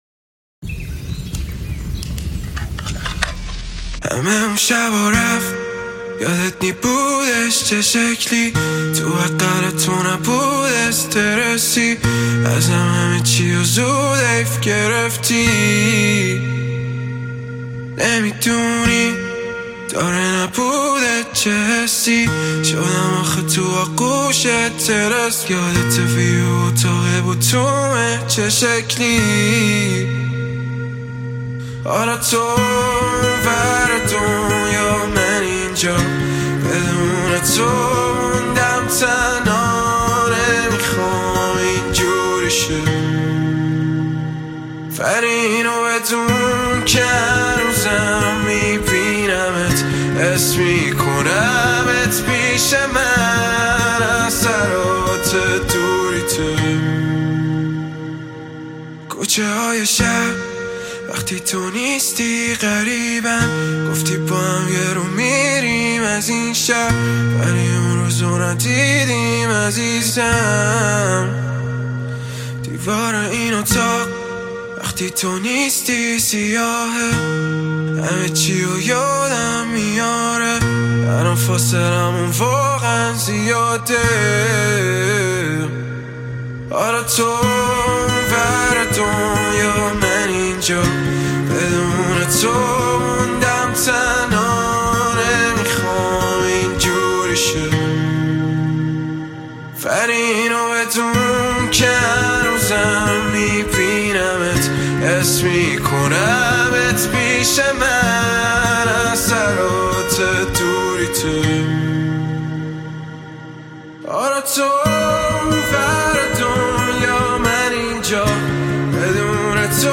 زیبا و احساسی